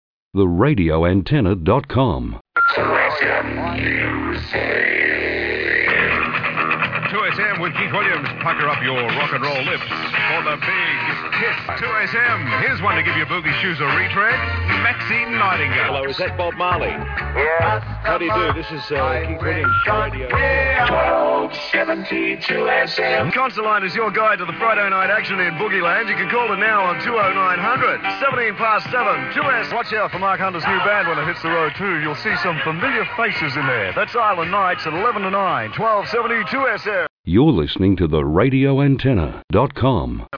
from a 1981 presentation tape that was used by the sales team
note the use of vocoders and a very hyped style, must have been prior to Marleys passing  [contact-form][contact-field label=’Viewing Source(tablet desktop etc) ‘ type=’text’/][contact-field label=’Name’ type=’name’ required=’1’/][contact-field label=’Email’ type=’email’ required=’1’/][contact-field label=’Comment’ type=’textarea’ required=’1’/][contact-field label=’Website’ type=’url’/][/contact-form]